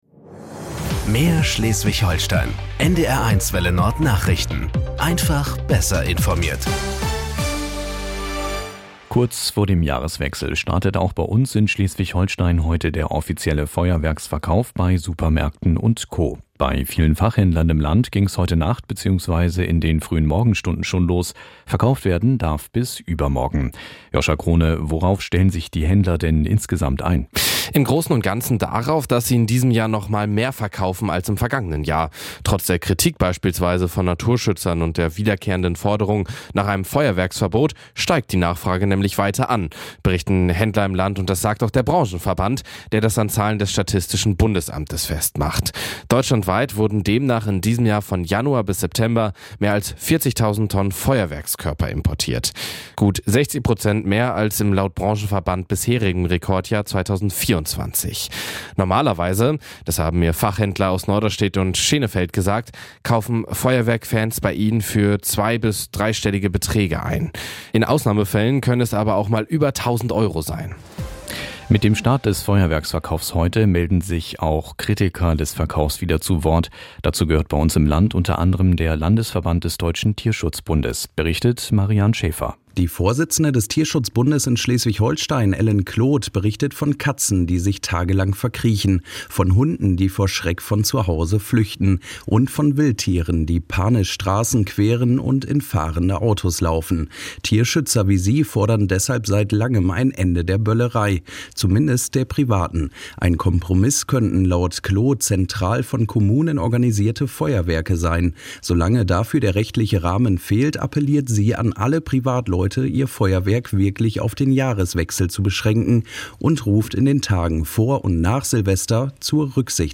Nachrichten 06:00 Uhr - 29.12.2025